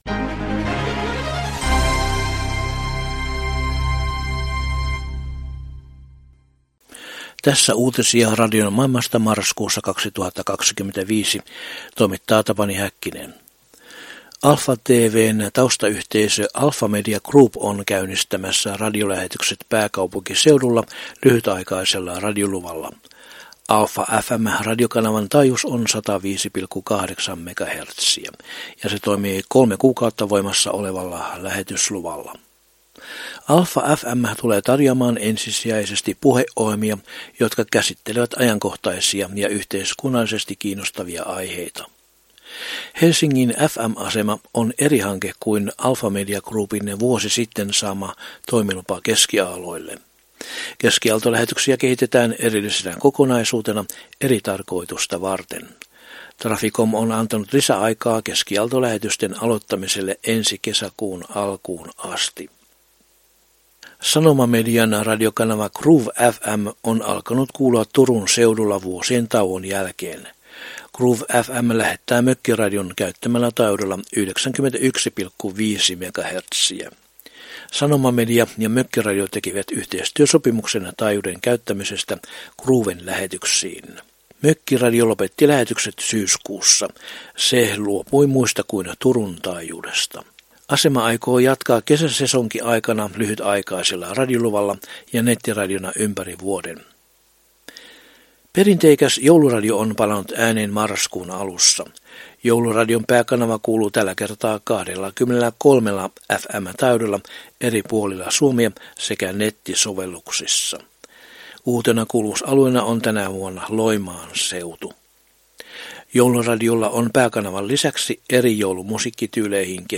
Marraskuun 2025 uutislähetyksessä aiheina ovat muun muassa Alfa Median lyhytaikainen radioasema Helsingissä, Jouluradion paluu, Radio Säteilyn syyskauden lähetysten alkaminen ja Norjan digiradiolähetykset Utsjoella.